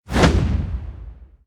acher_skill_backkick_02_swing_a.ogg